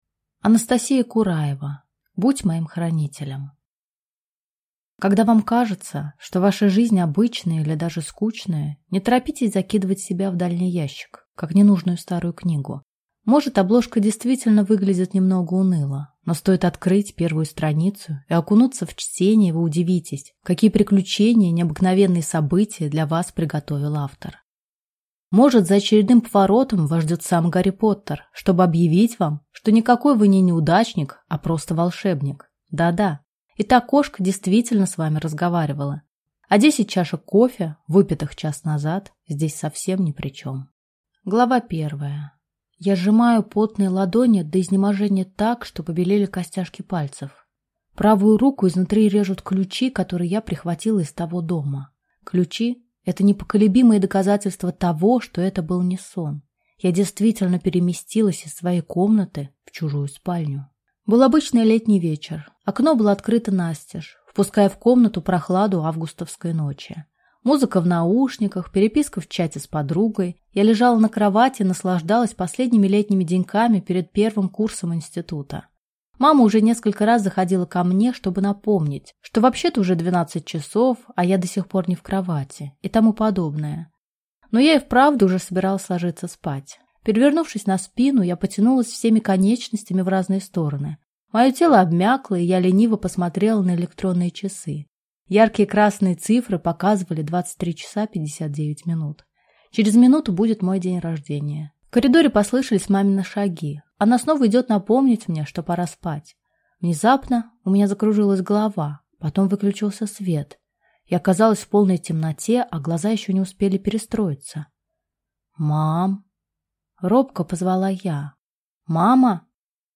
Аудиокнига Будь моим Хранителем | Библиотека аудиокниг